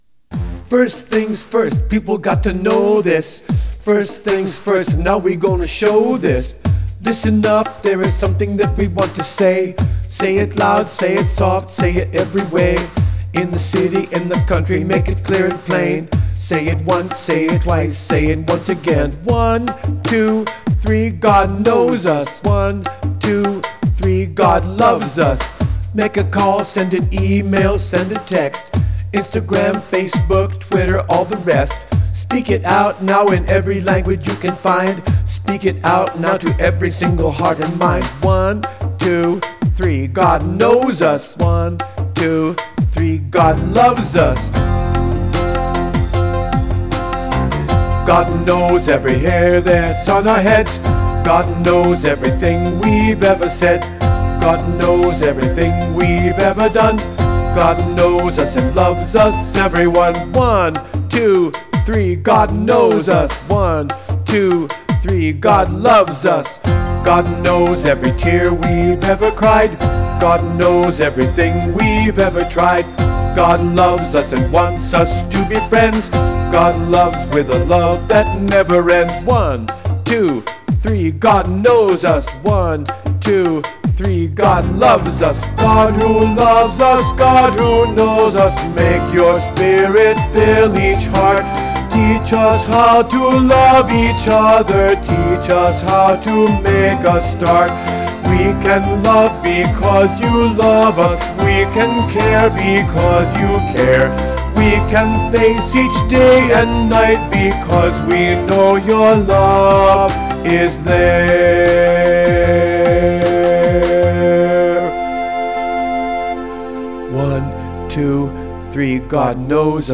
playful new song